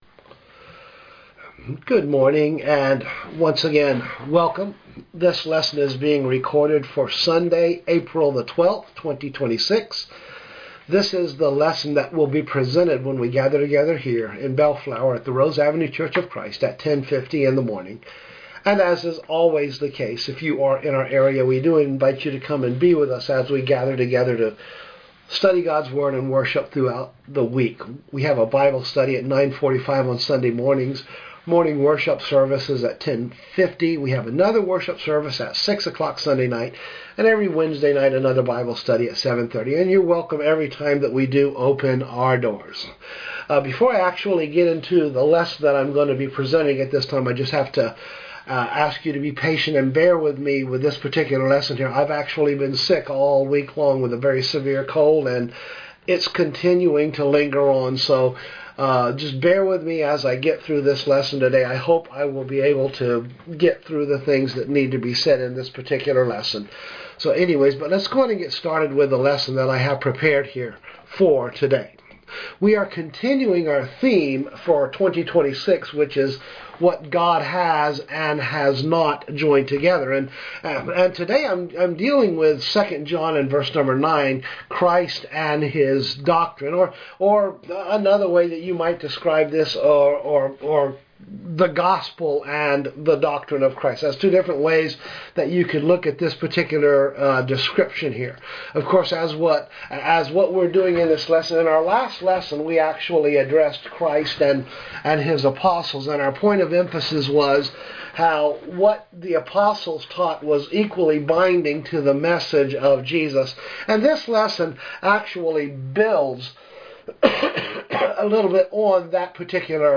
I recorded this lesson on 4/11 and you can tell I have a very bad cold.
Thank you for your patience and understanding with my voice.